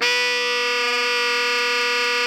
Index of /90_sSampleCDs/Giga Samples Collection/Sax/ALTO 3-WAY
ALTO GR B 3.wav